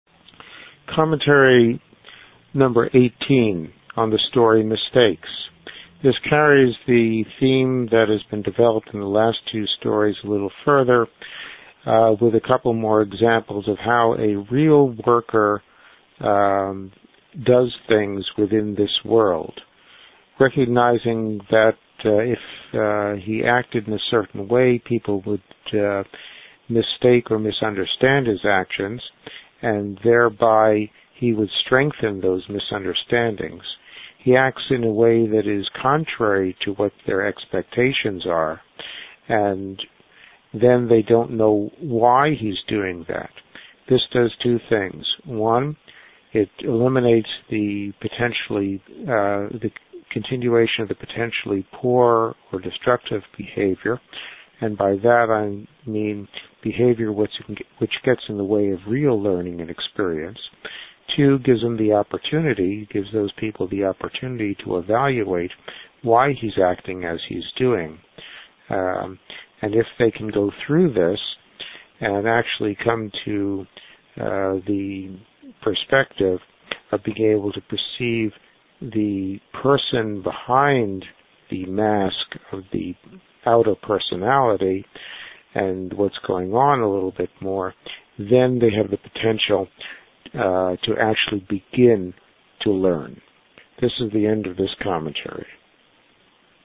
Note: each of the following begins with a reading of the story and then the commentary.